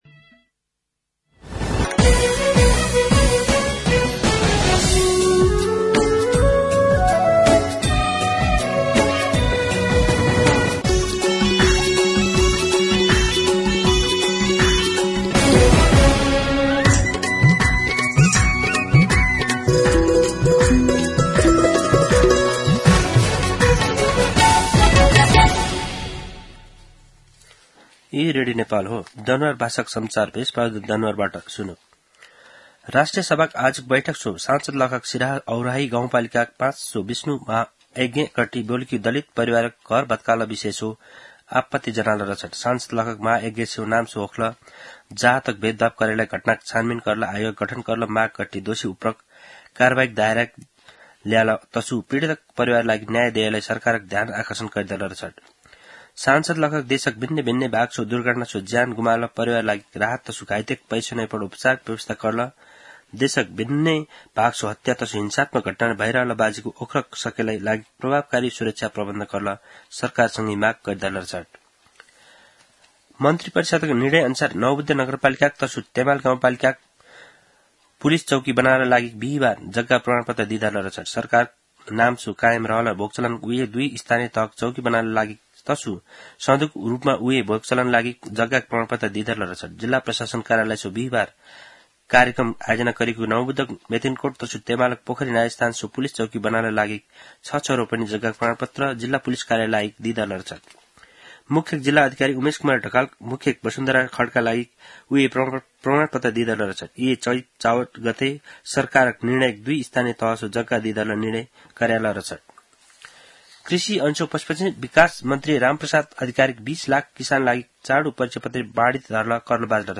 An online outlet of Nepal's national radio broadcaster
दनुवार भाषामा समाचार : ८ चैत , २०८१
Danuwar-News-2.mp3